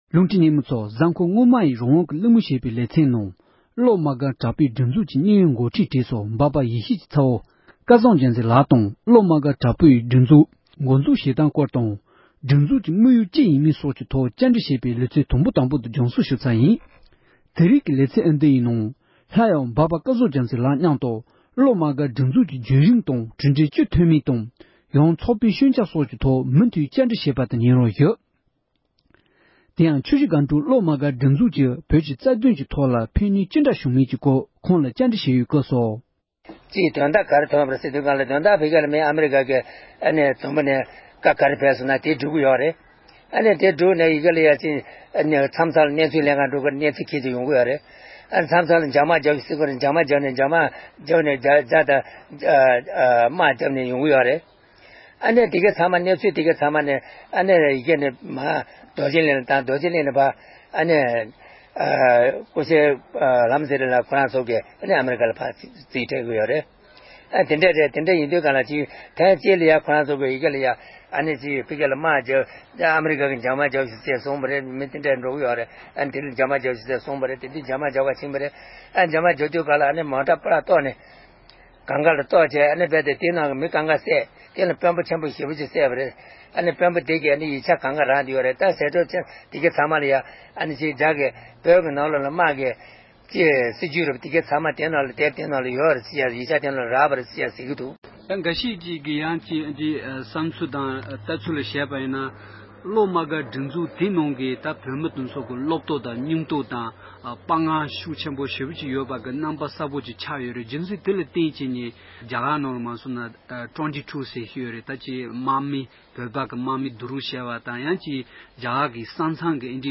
བཀའ་འདྲི་ཞུས་པའི་ལེ་ཚན་དང་པོ་དེ་གསན་རོགས་དང༌༎